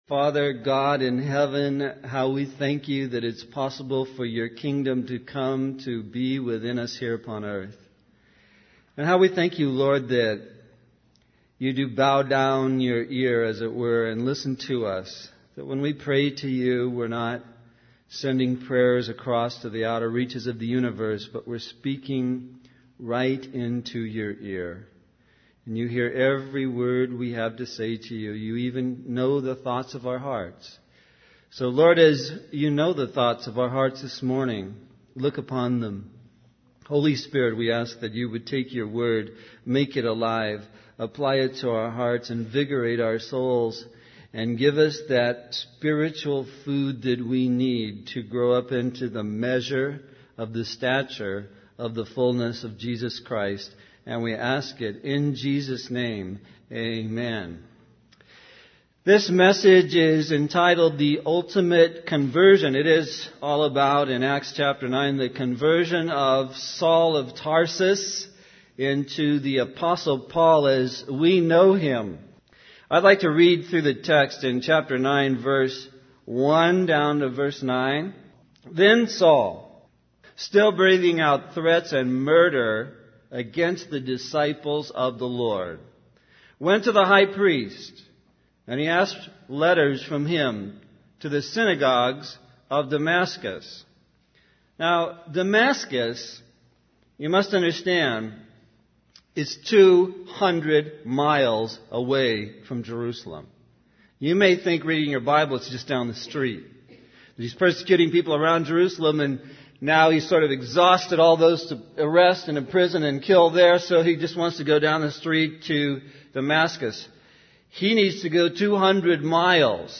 In this sermon, the speaker describes a man who was physically unattractive and had a funny appearance.